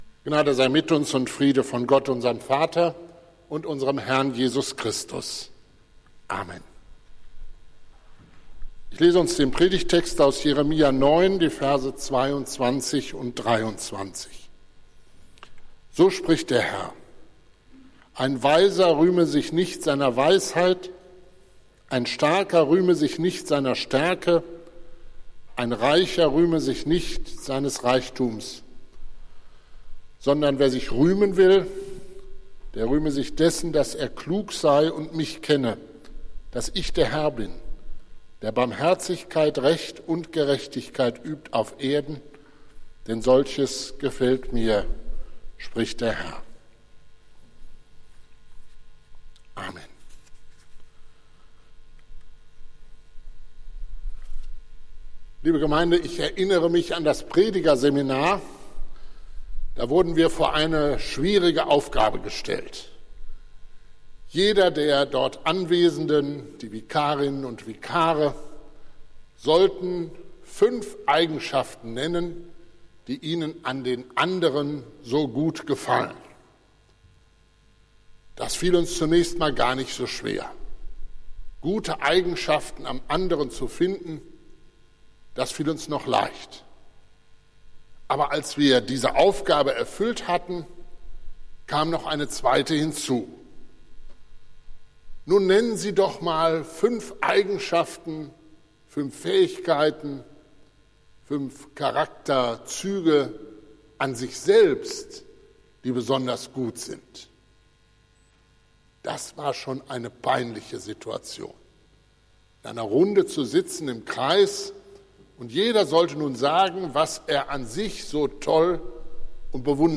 Predigt des Gottesdienstes aus der Zionskirche vom Sonntag, 13.02.2022
Wir haben uns daher in Absprache mit der Zionskirche entschlossen, die Predigten zum Nachhören anzubieten.